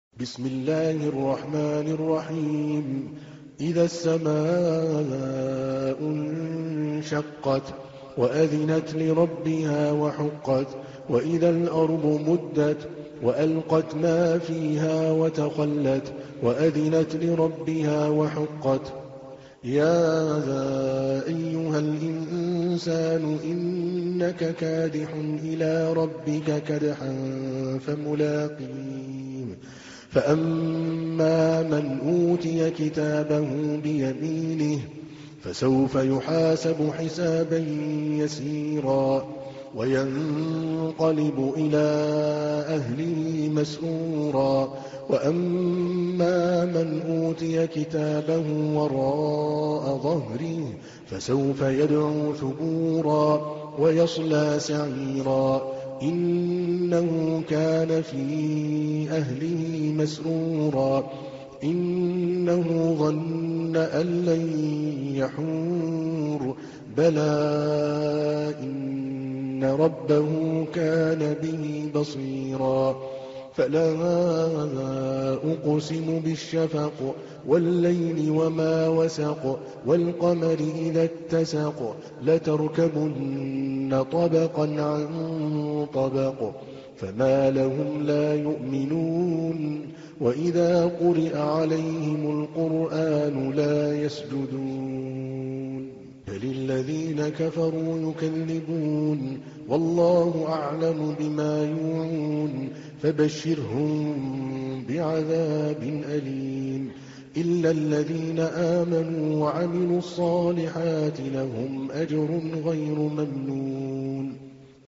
تحميل : 84. سورة الانشقاق / القارئ عادل الكلباني / القرآن الكريم / موقع يا حسين